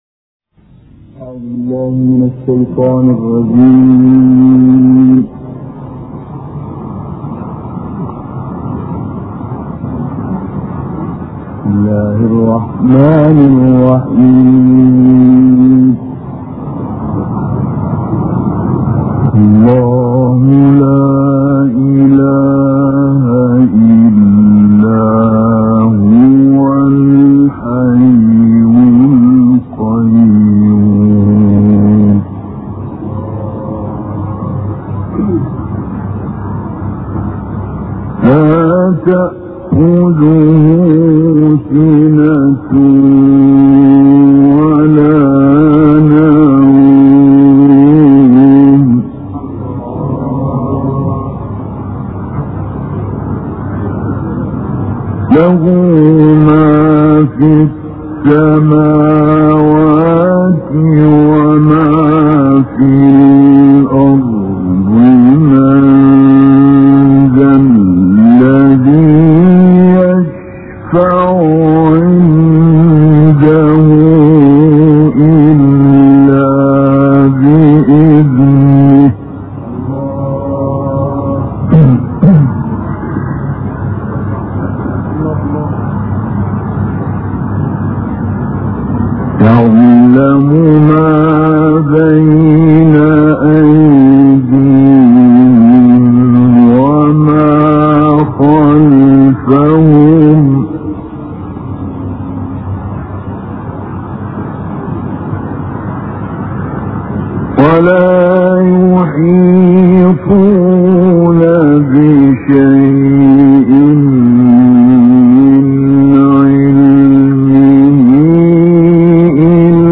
تلاوات قرآنية